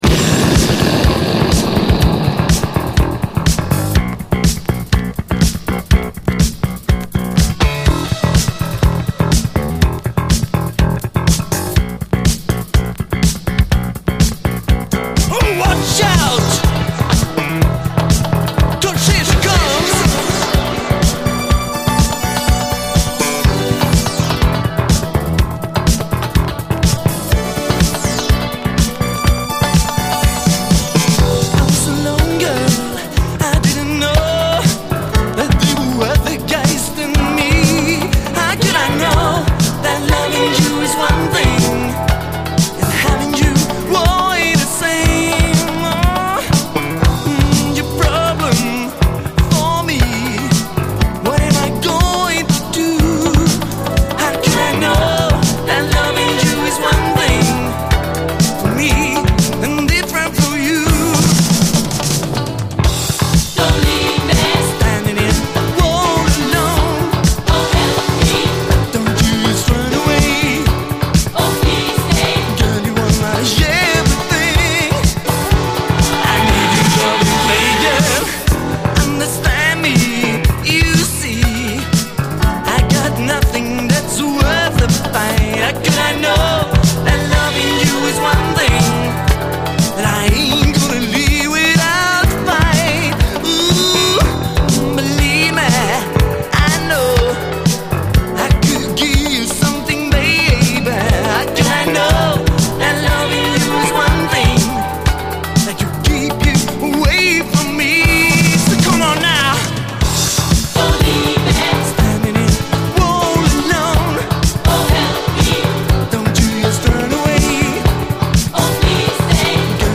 SOUL, 70's～ SOUL, DISCO, 7INCH
スウェーデン産レア・ブギー・モダン・ソウル45！
後半のシンセ・ソロもイカしてます！